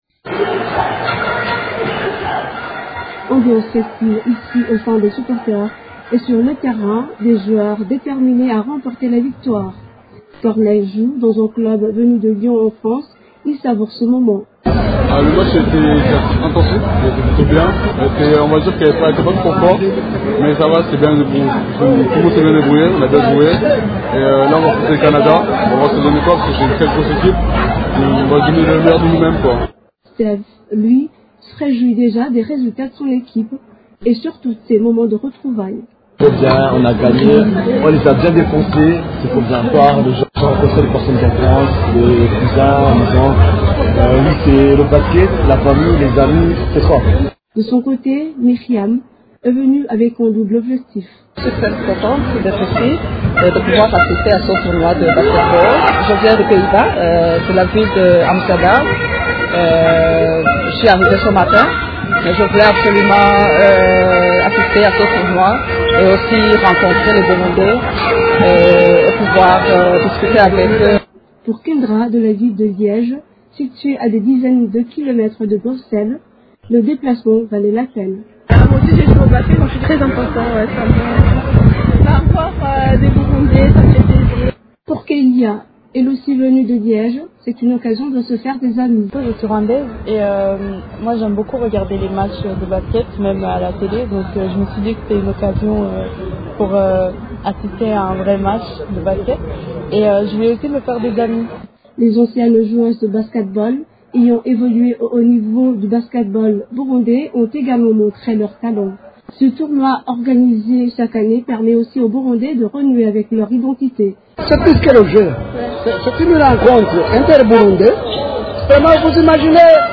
Reportage-TBBI-1.mp3